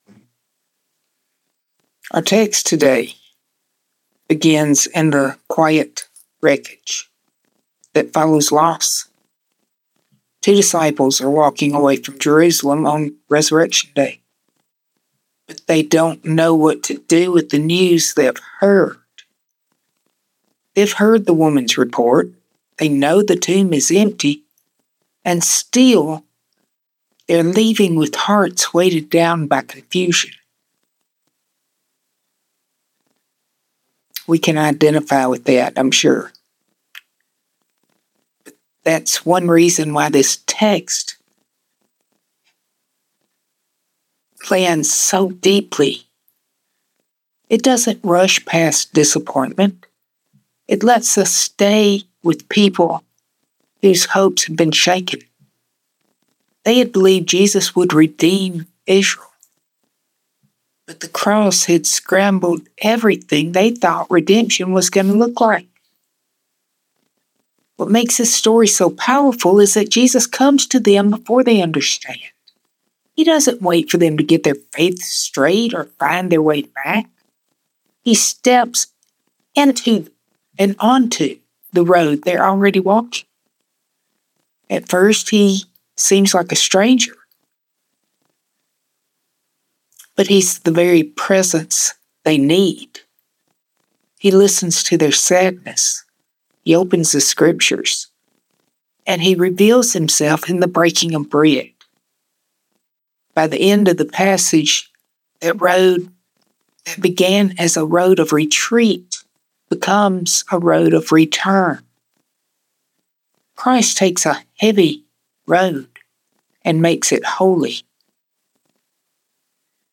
00:10 Direct Link to sermon Sermon Handout Recent Sermons He is Not Here The King Who Comes Gently Come Out Opening Our Eyes Meeting Jesus at the Well